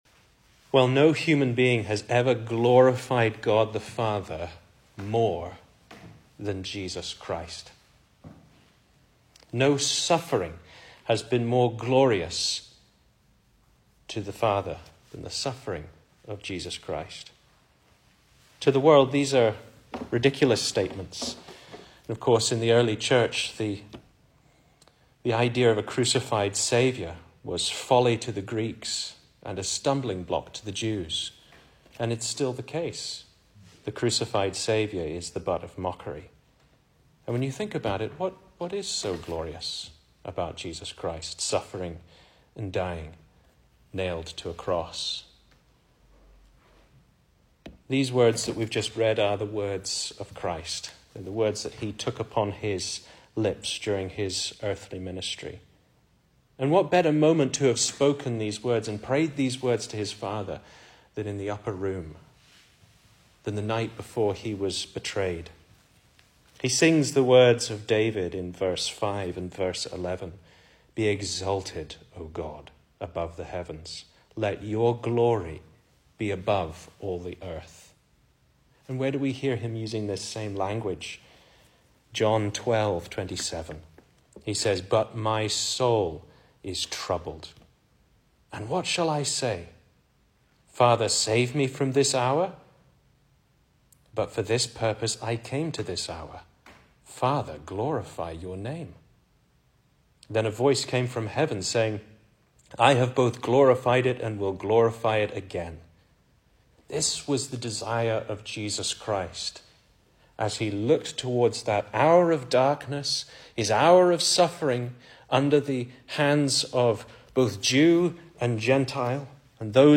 Service Type: Weekday Evening